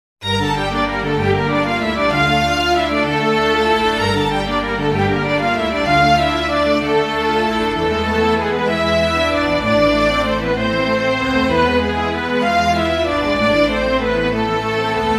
струн. квинтет